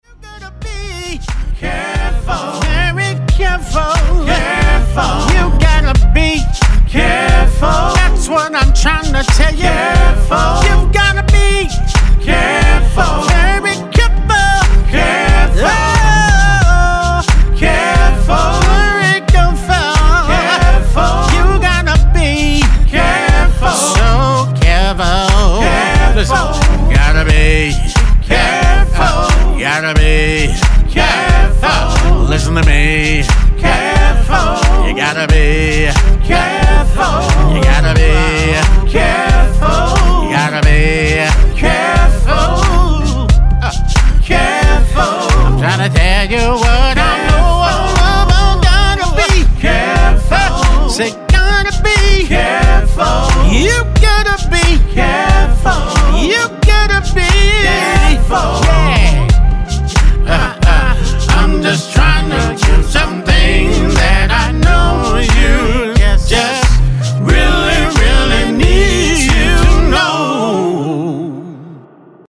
RHYTHMIC SOUL